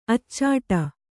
♪ accāta